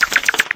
takeoff.ogg